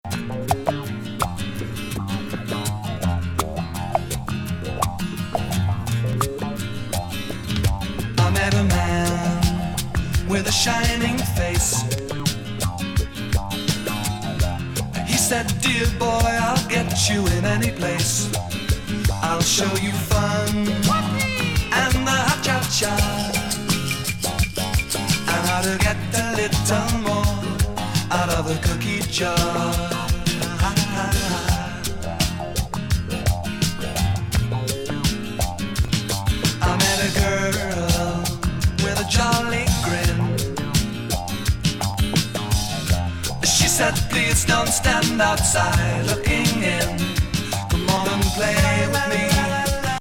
ポップなライト・バレア・ロック